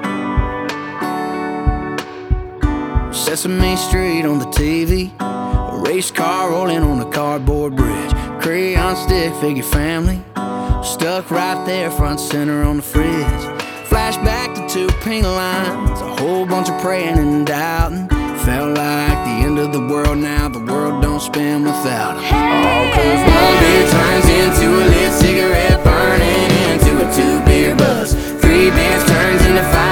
• Country
the singer waxes contemplative in his new single